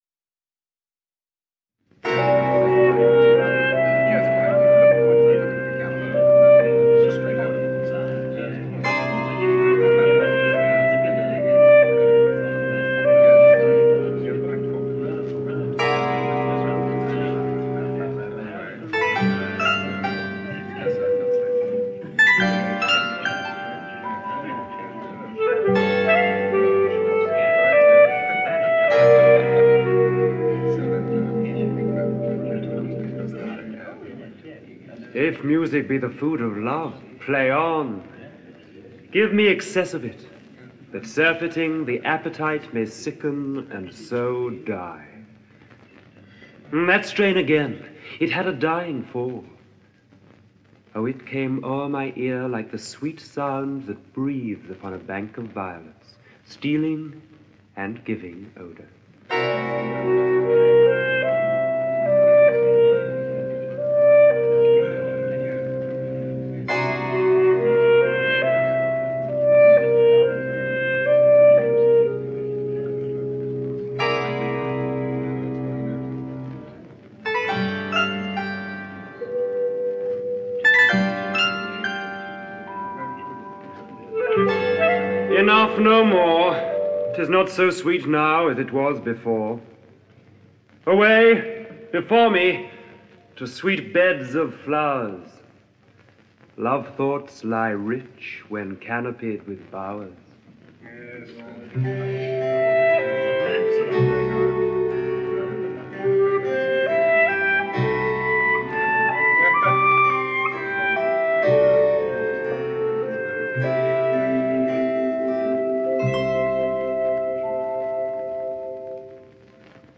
Twelfth Night (EN) audiokniha
Ukázka z knihy